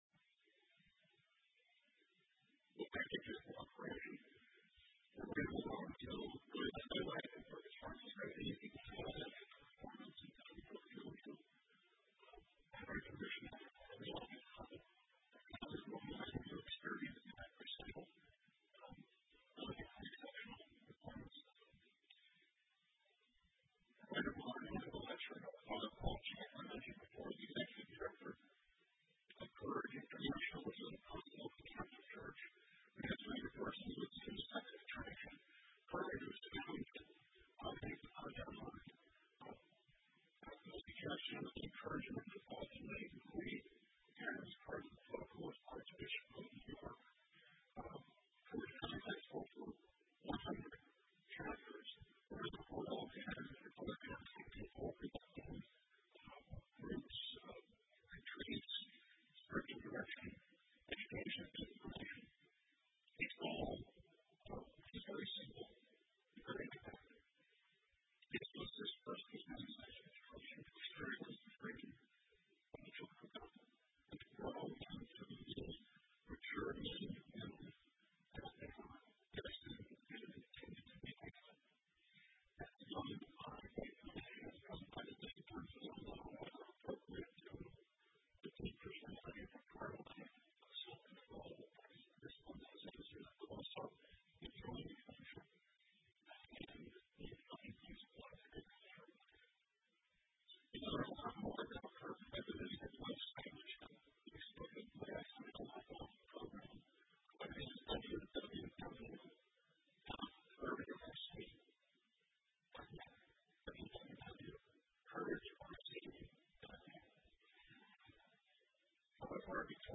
Media coverage of this lecture here.